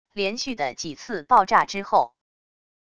连续的几次爆炸之后wav音频